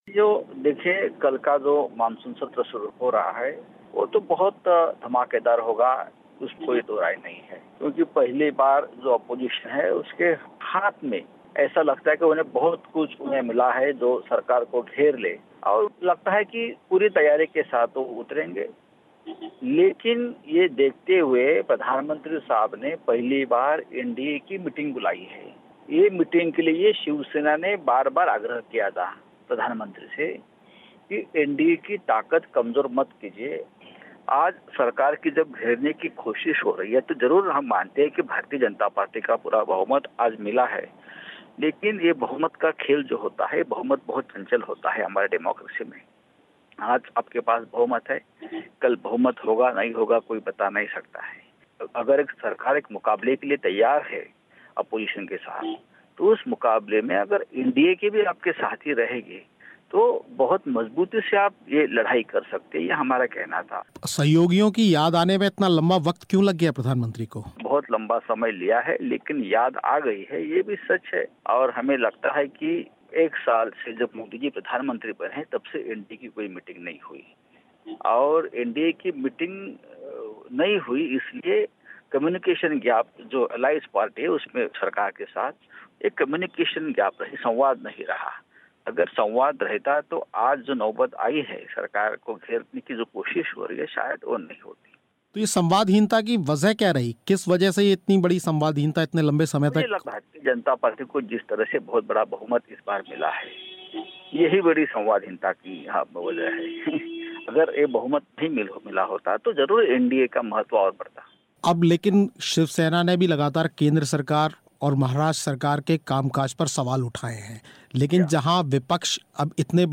ख़ास बातचीत